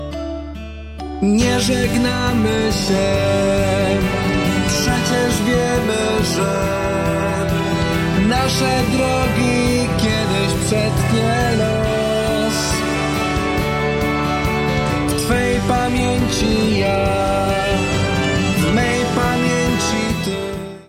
Muzyka
Autorska piosenka, idealna na zakończenie roku szkolnego.